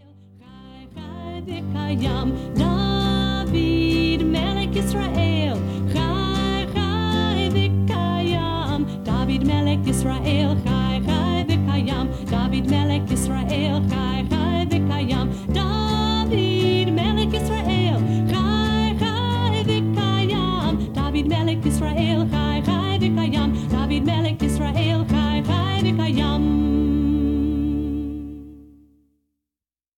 Traditional songs in Hebrew & English.
(Folk)